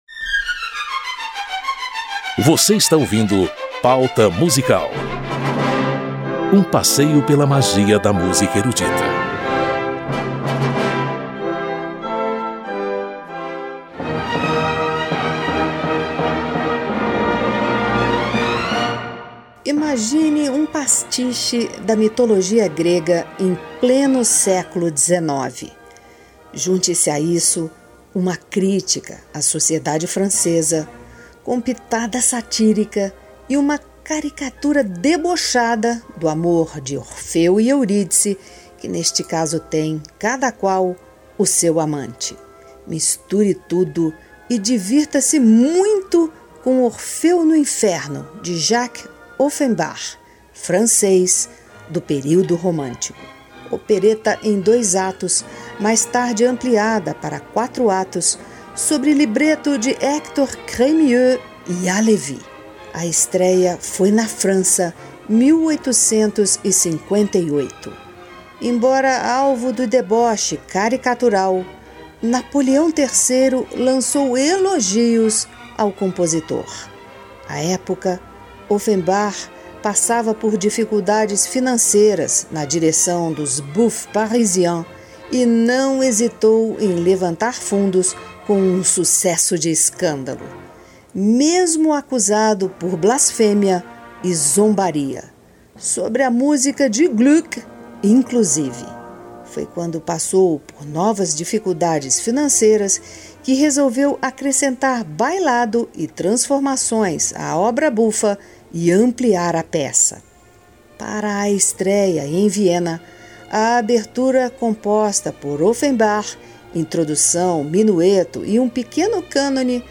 Aberturas, prelúdios e interlúdios de famosas óperas e operetas em série especial de 3 edições. Nesta primeira parte: Lohengrin (Richard Wagner), O Morcego (Johann Strauss II), A Pega Ladra (Gioacchino Rossini), O Franco Atirador (Carl Maria von Weber) e Orfeu no Inferno (Jacques Offenbach). Orquestra Filarmônica de Berlim, regida pelos maestros Klaus Tennstedt e Herbert von Karajan.